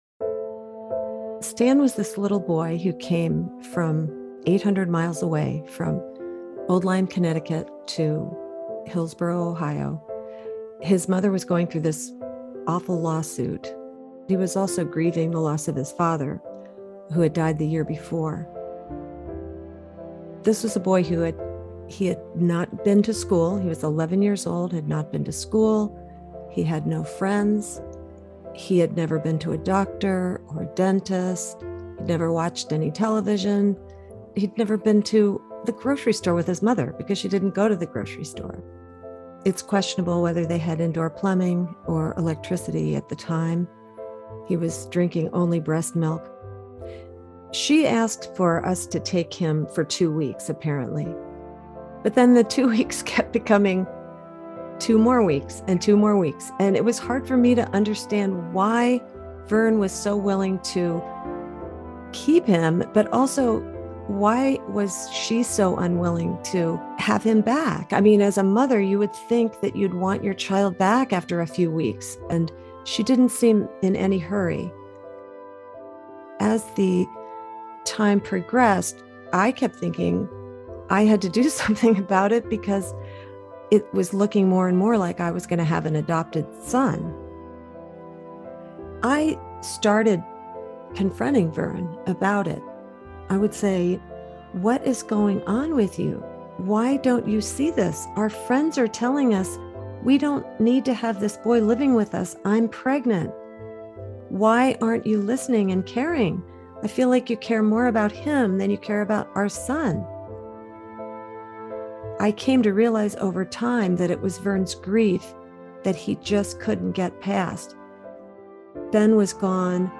Interviews and audio